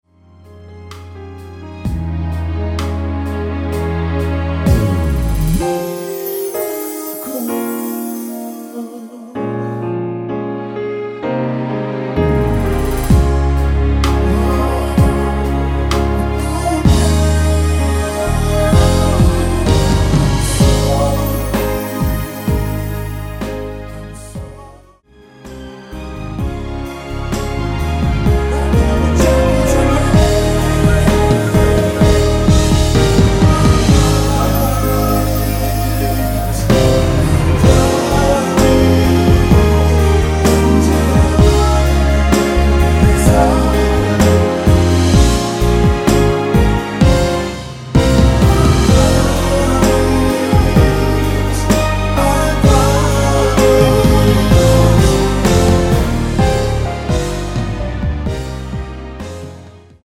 (-2)내린 코러스 포함된 MR 입니다.(미리듣기 참조)
Ab
앞부분30초, 뒷부분30초씩 편집해서 올려 드리고 있습니다.
중간에 음이 끈어지고 다시 나오는 이유는